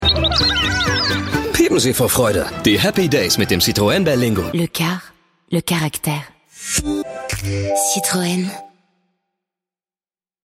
Synchron/Dubbing, Werbung, Games, HĂ¶rspiel, Off-Voice, Voice-Over, Sonorig, Fein, Klar, Sonorig, Warm, Markant, Entspannt, Energetisch, Dominant, Charmant, Jugendlich, Frisch, LĂ€ssig, Cool, MĂ€nnlich, Markant, VerfĂŒhrerisch, Jung, Hip, Provokativ, Herausfordernd, DĂŒster, BĂ¶se, Verrucht, Emotional, Weich, Sensibel, Lustig, SchrĂ€g, Komisch, Understatement, Trickstimme, Native Speaker (Griechisch, Deutsch), Englisch (AE/BE), Tenor, Verspielt, Charge
Sprechprobe: Industrie (Muttersprache):
Actor, Dubbing, Advertisement, Games, Audio Drama, Voice-Over, Native Speaker (Greek/German), English (US/UK), Warm, Masculin, Sensitive, Clear, Laid-Back, Cool, Young, Fresh, Hip, Charming, Seductive, Provoking, Challenging, Dark, Mad, Angry, Wicked, Emotional, Understatement, Comical, Funny, Comic-Voice, Playful, Tenor